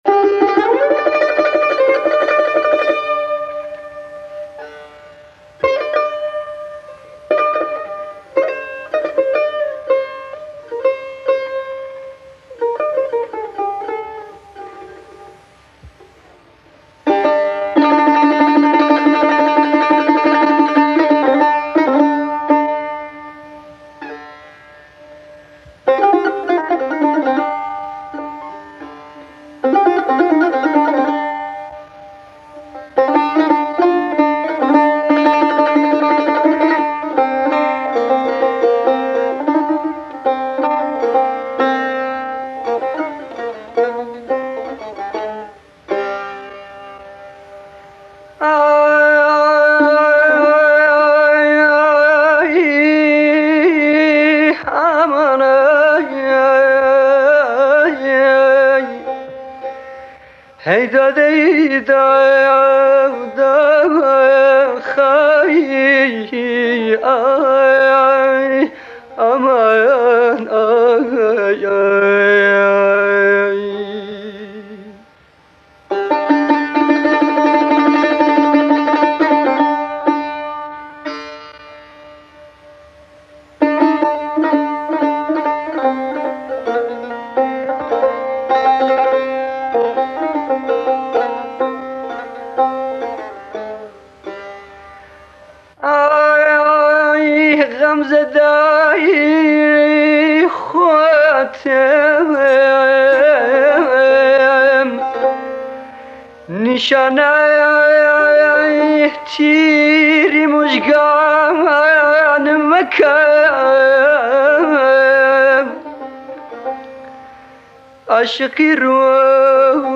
مەقامی حسێنی